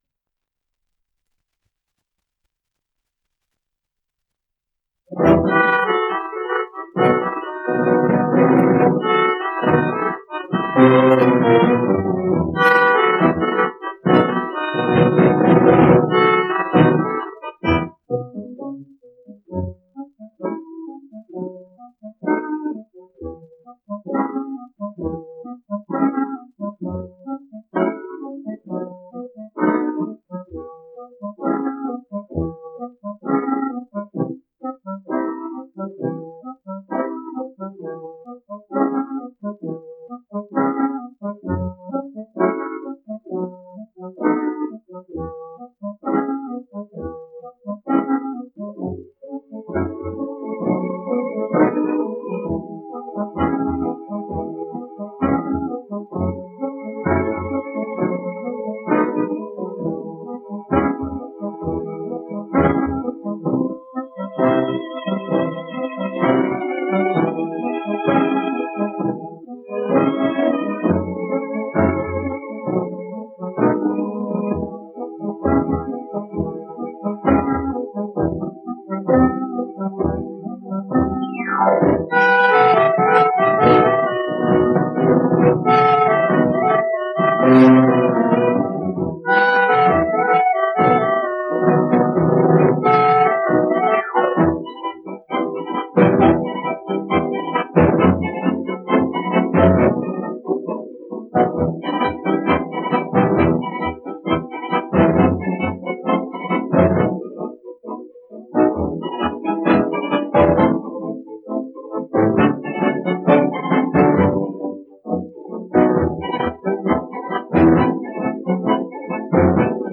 1 disco : 78 rpm ; 25 cm